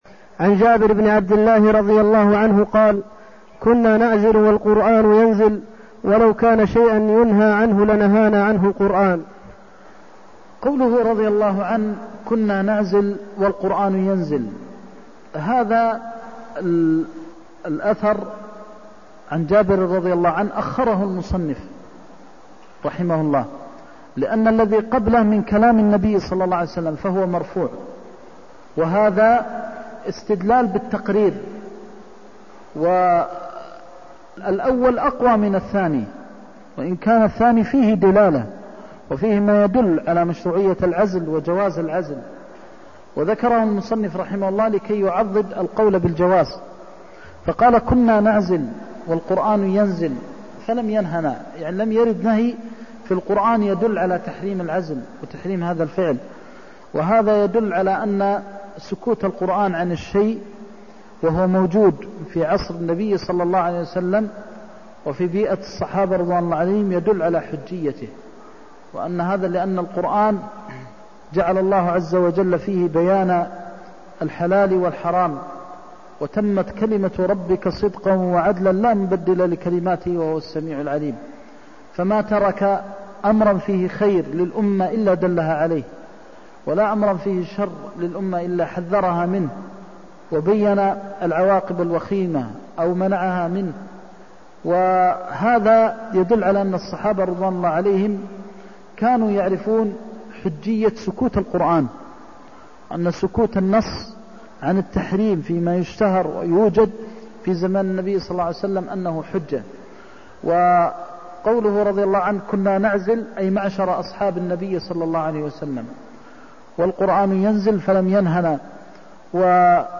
المكان: المسجد النبوي الشيخ: فضيلة الشيخ د. محمد بن محمد المختار فضيلة الشيخ د. محمد بن محمد المختار كنا نعزل والقرآن ينزل (312)